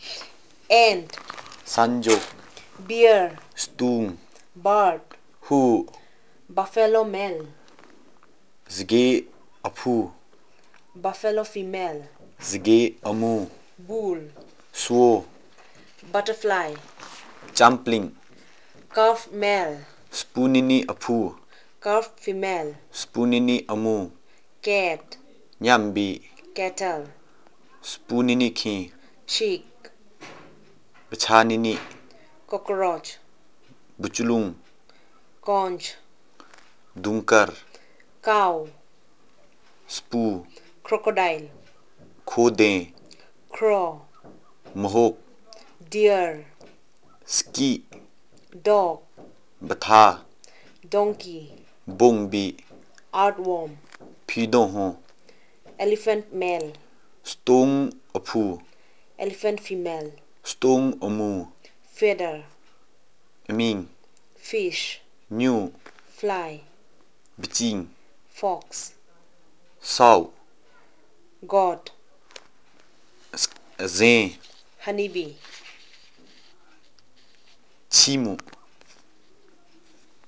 Elicitation of words about domestic animals, birds and related and insects and related